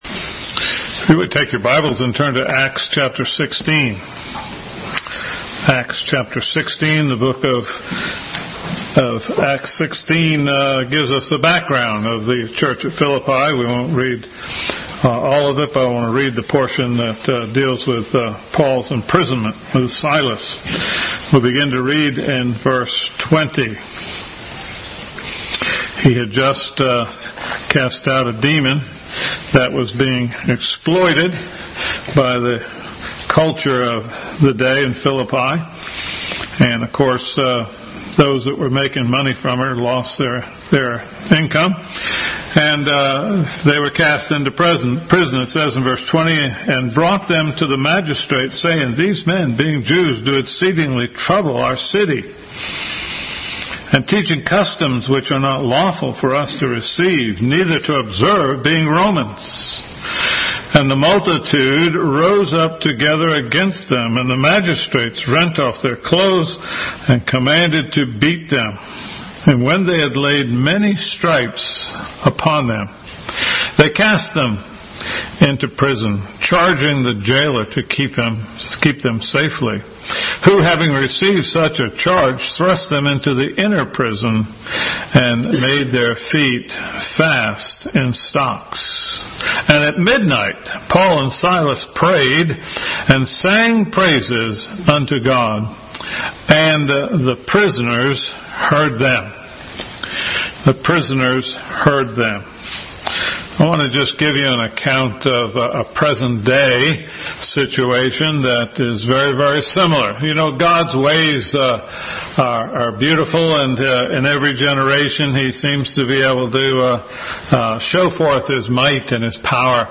Bible Text: Acts 16:20-34, Philippians 1:4-6, 1:9, 3:3, 3:10, 4:1, 4:10-11, 4:6-7, Hebrews 12:2, 2 Corinthians 4:15-18 | Family Bible Hour Message – The Happy Prisoner. A look at Paul during the time he wrote the Epistle to the Philippians.